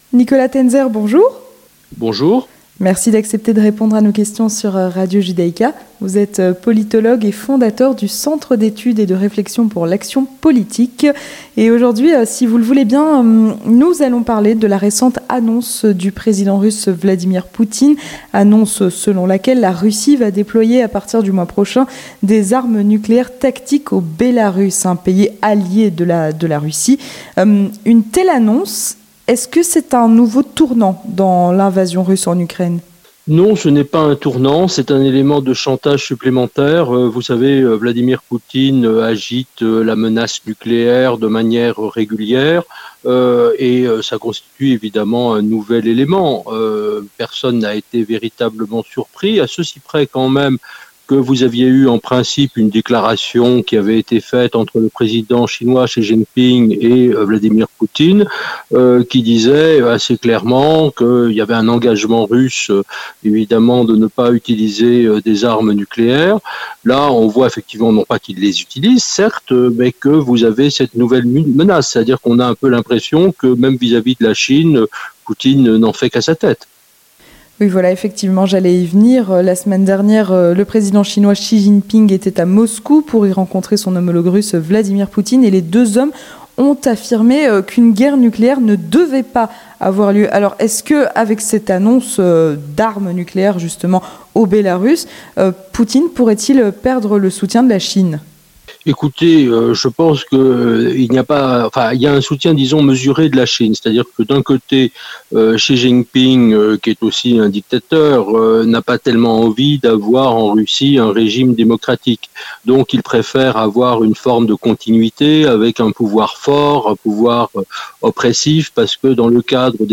L'Entretien du 18h - La Russie va déployer des armes nucléaires tactiques au Bélarus